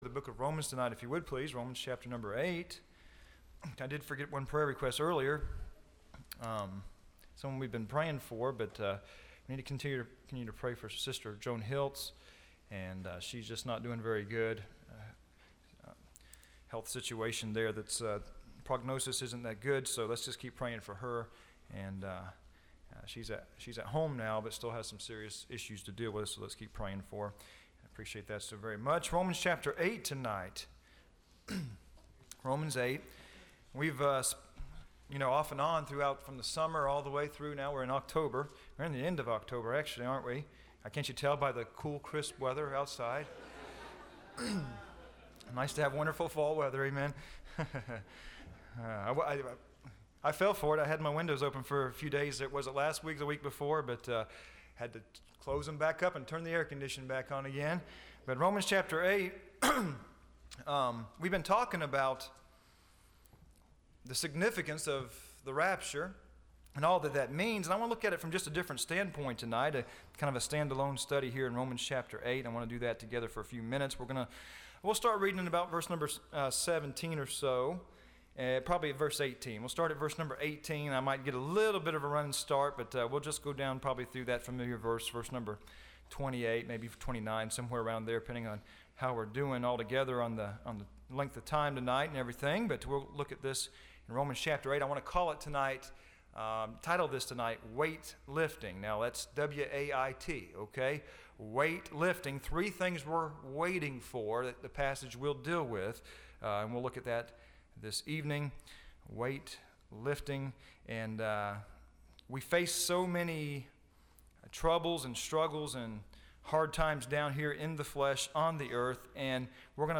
Listen to Message
Service Type: Wednesday